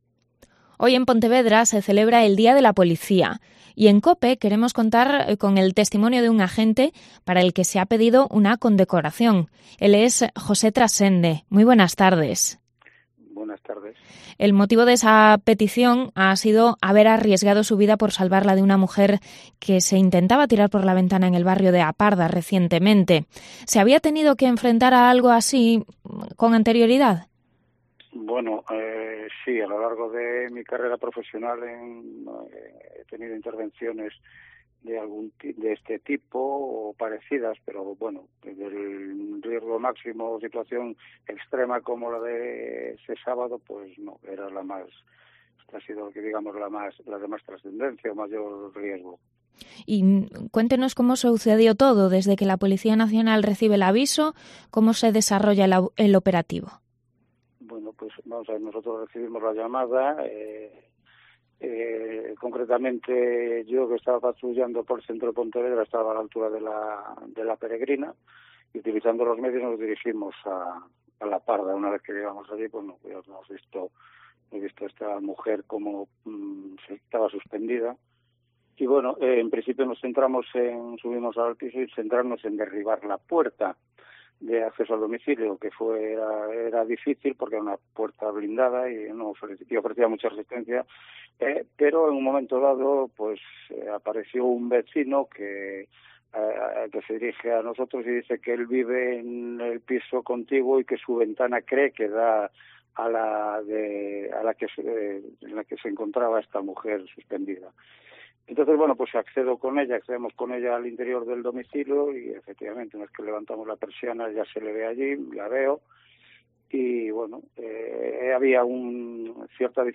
Entrevista al agente de la Policía Nacional que evitó que una mujer cayese por la ventana en Pontevedra